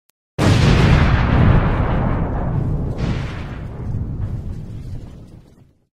Free_Green_Screen_Explosion_Effect_Sound_Effect_Short_Version